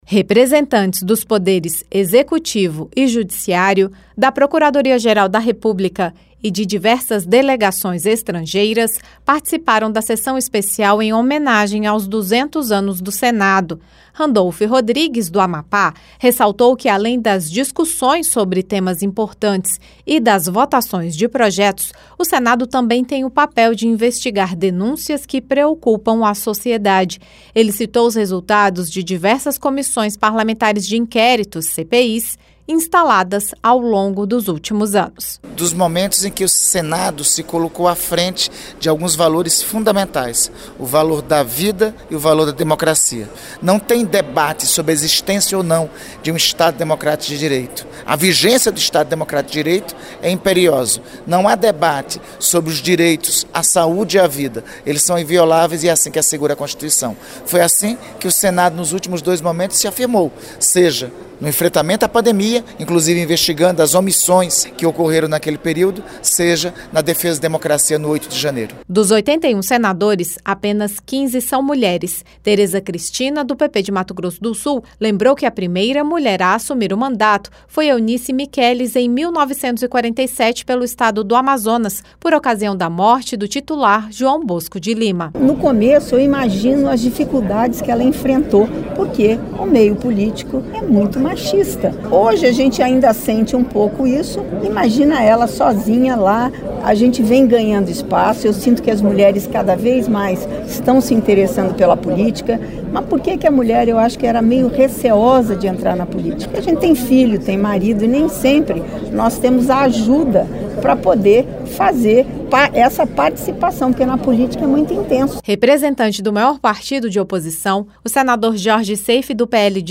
Na sessão especial dos 200 anos do Senado, parlamentares destacaram diferentes aspectos da instituição. O senador Randolfe Rodrigues (AP) citou o trabalho das Comissões Parlamentares de Inquérito (CPIs), em especial, a da Pandemia, em 2021, e a do 8 de Janeiro, em 2023. A senadora Tereza Cristina (PP-MS) lembrou que apenas 15 mulheres têm mandato no Senado, representando 18% do total.